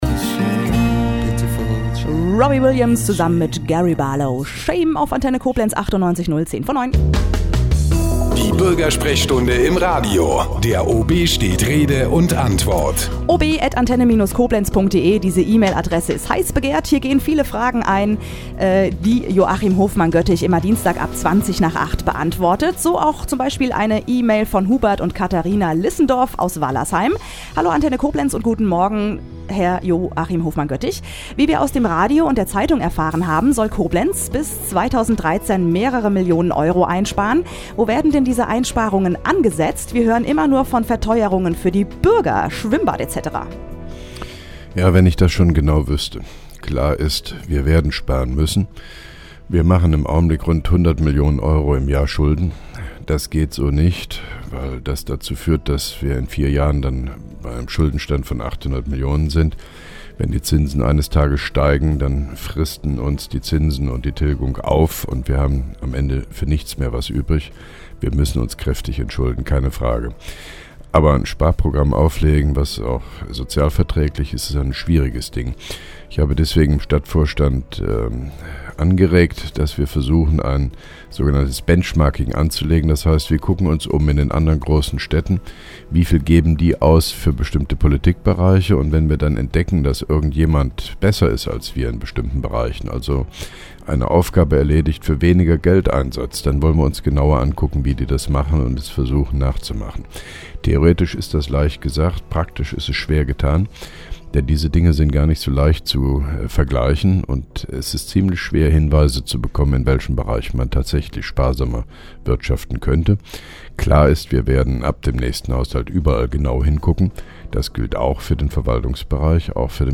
(4) Koblenzer OB Radio-Bürgersprechstunde 21.09.2010